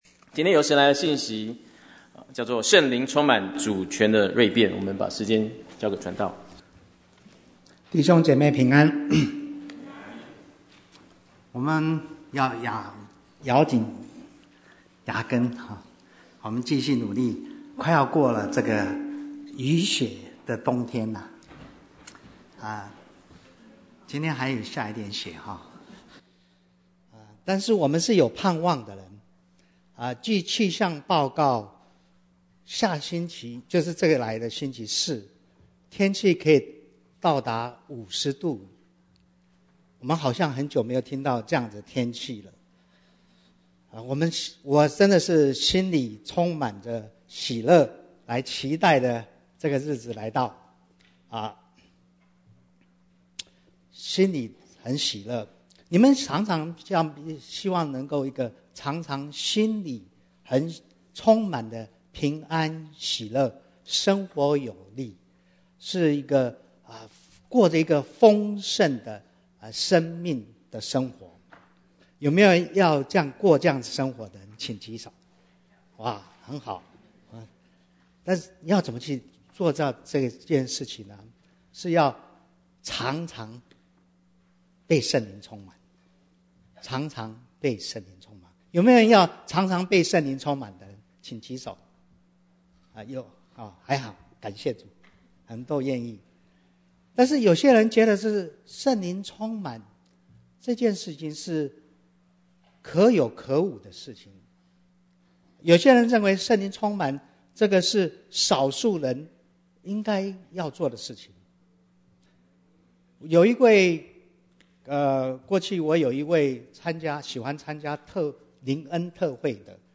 Bible Text: Acts 4:31-37 | Preacher